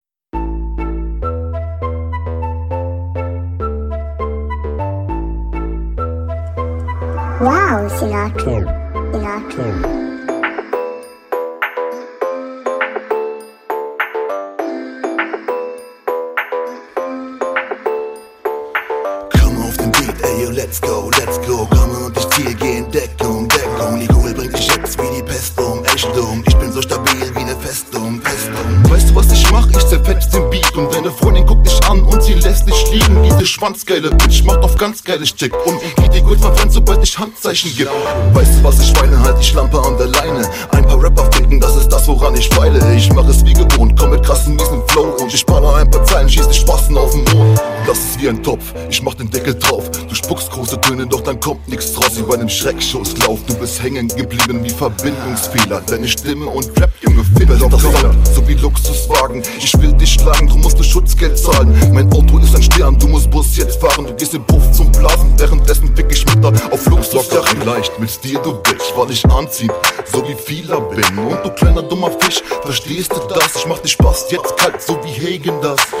Beatwahl: Fresh und genau das liegt dir, Stimme geschmeidig locker gefällt mir.
Hier gefällt mir deine Mische schon deutlich besser. Die Doubles sind auch deutlich besser gesetzt.
Cooler Beat.
Reime sind besser geworden, leider Doppelfehler zu hören.